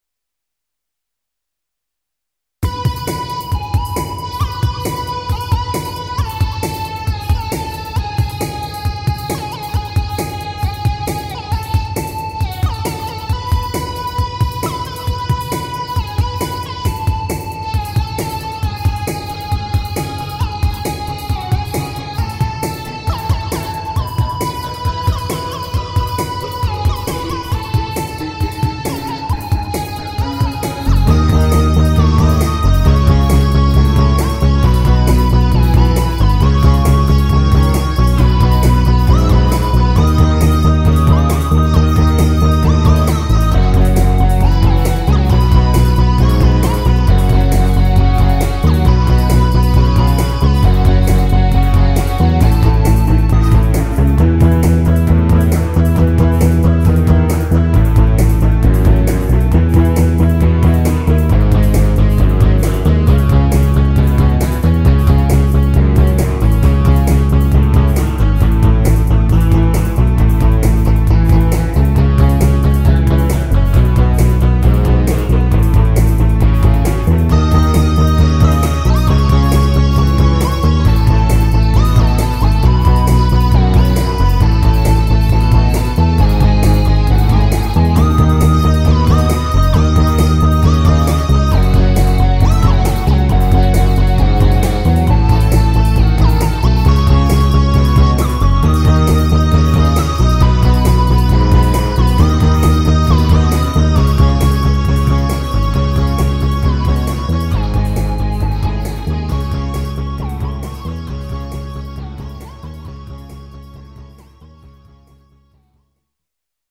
Hopalong (1.50) Mi (E) -  135
Plugins :  Font12! , Evm Bass Line , MDA EPiano , Synth1 ,
Drum loop : Arythm
Mode : Phrygien